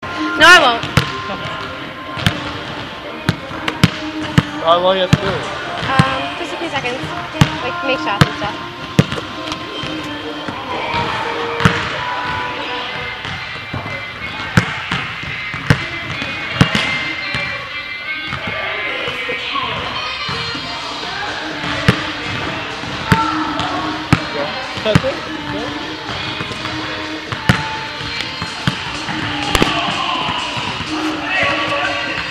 Field Recording
Walking into Hague in the Netherlands Sounds- the opening of a door, the swipe thing beeping, keys